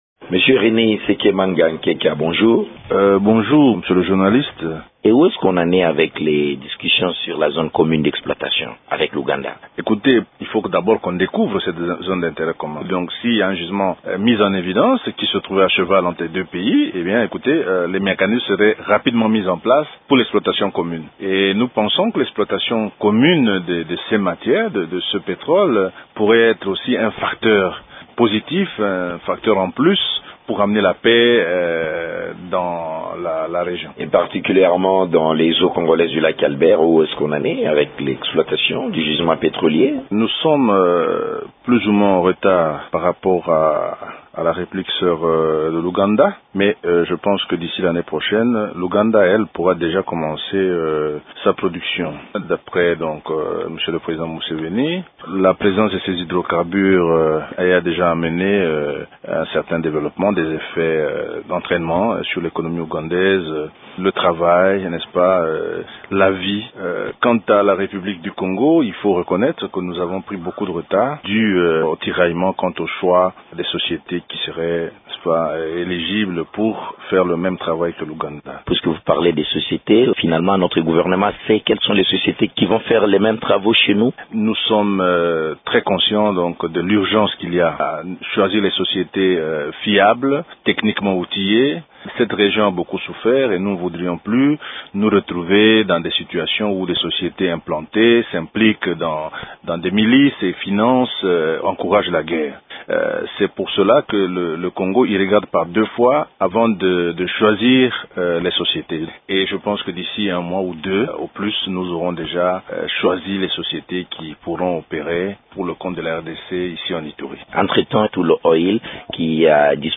Des éléments de réponse avec Réné Isekemanga Nkeka, ministre congolais des hydrocarbures